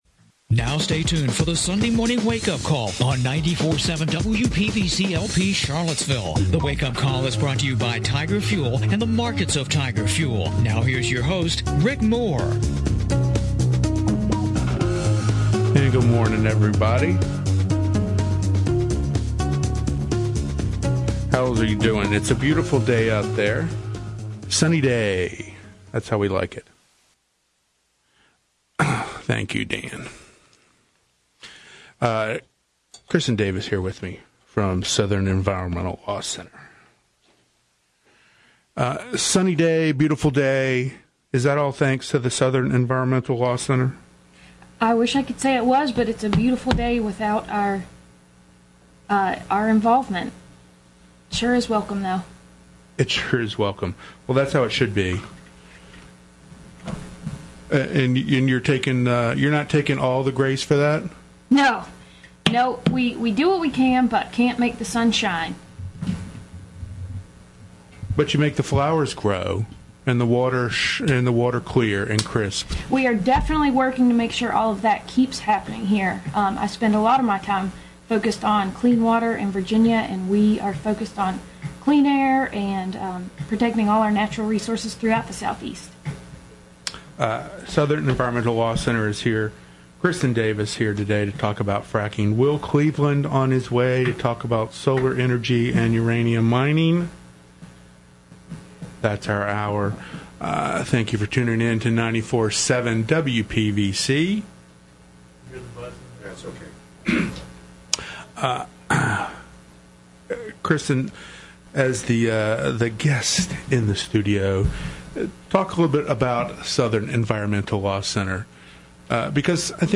The Sunday Morning Wake-Up Call is heard on WPVC 94.7 Sunday mornings at 11:00 AM.